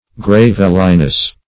Gravelliness \Grav"el*li*ness\, n. State of being gravelly.